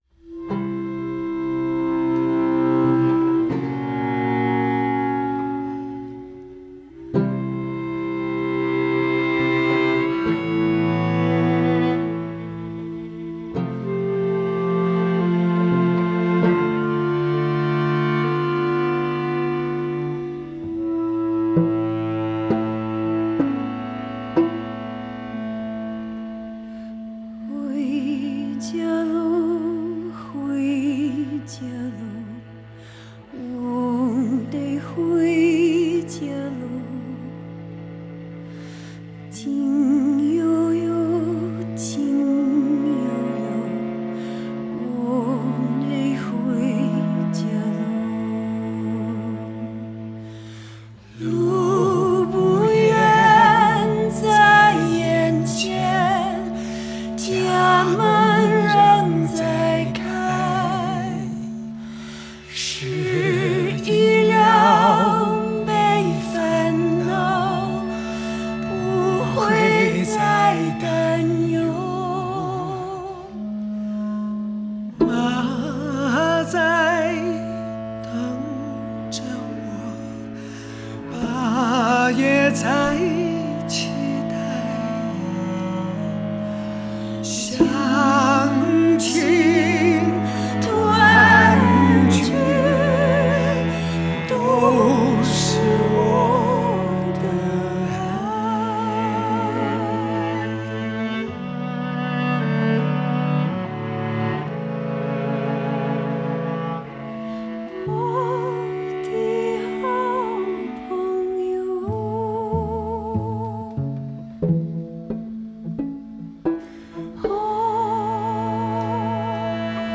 Жанр: Classical.